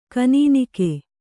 ♪ kanīnike